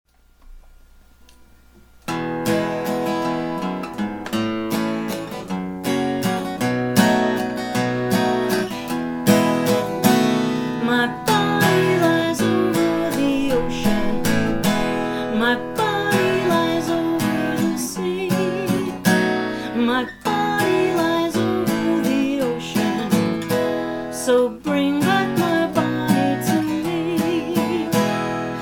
Home > Folk Songs